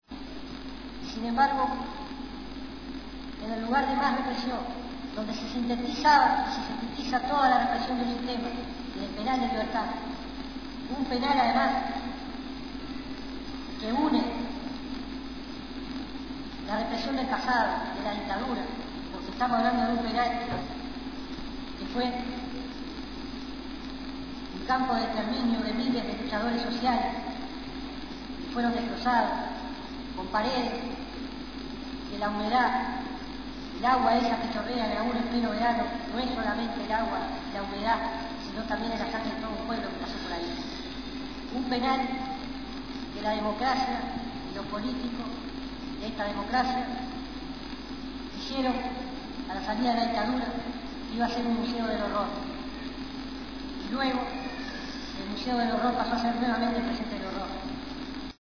A continuación extractos grabados por Indymedia/Uruguay de las palabras dichas por